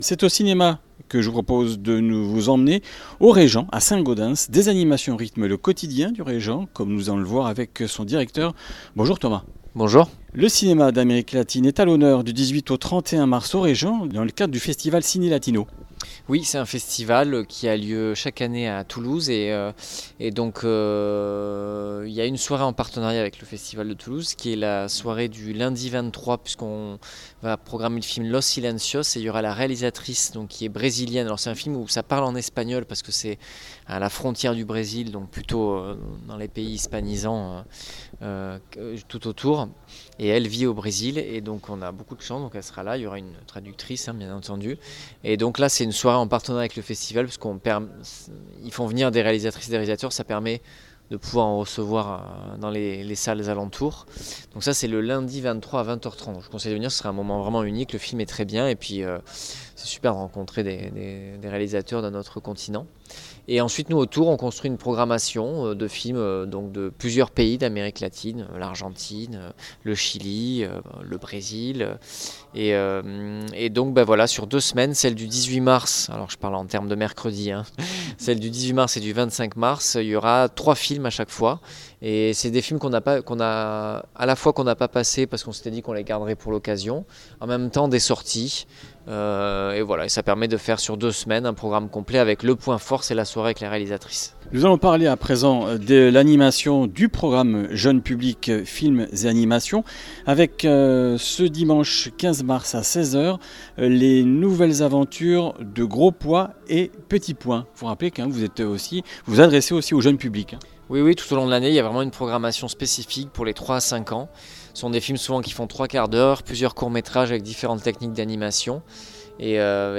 Comminges Interviews du 12 mars